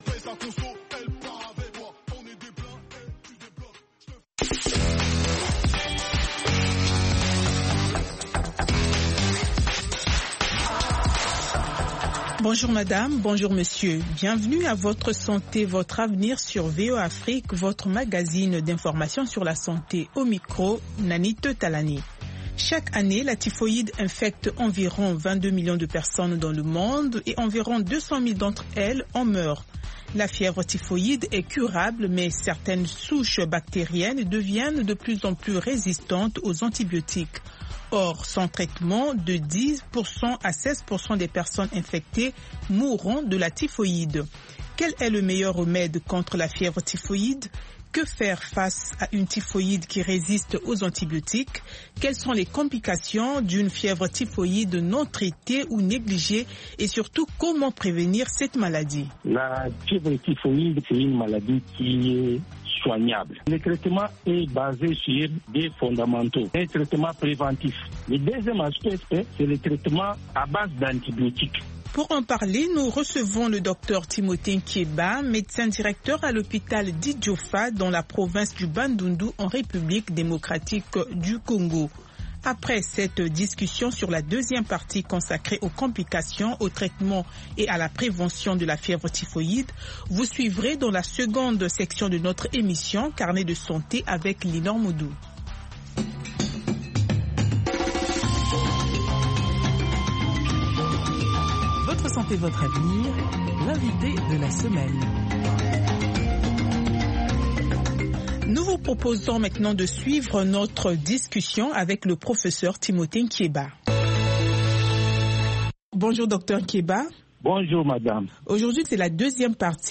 Fistules etc. Avec les reportages de nos correspondants en Afrique.